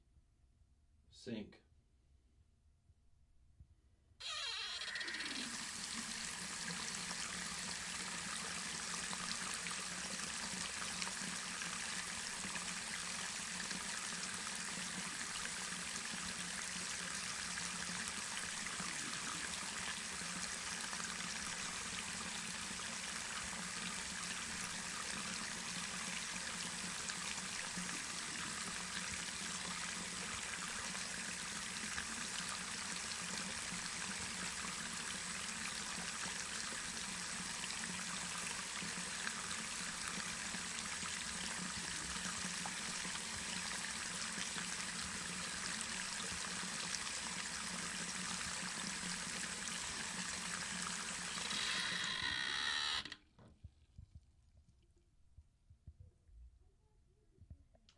水 " 自来水 2
描述：在水龙头再次关闭之前，水龙头打开，水在水槽中流动一段时间。
标签： 变焦 水槽 跑步 沐浴 倾倒 浴室 h4n 液体 丝锥 漏极 水龙头
声道立体声